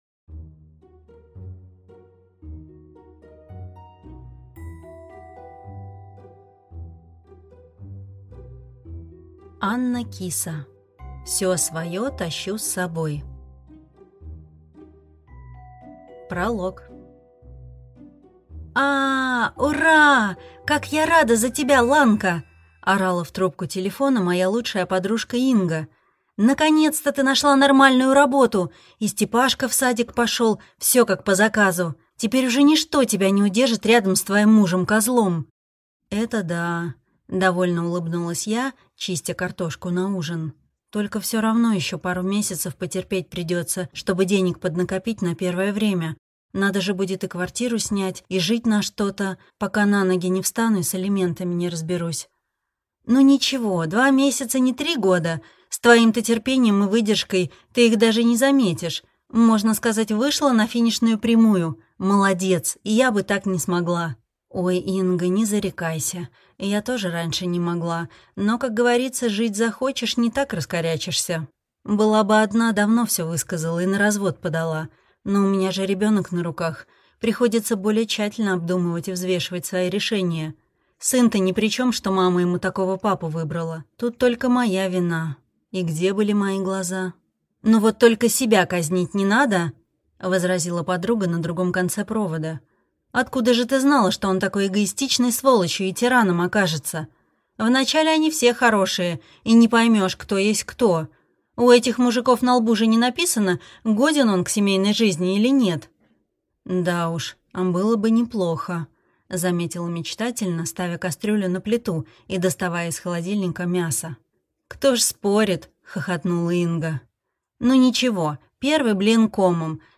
Аудиокнига Всё своё тащу с собой | Библиотека аудиокниг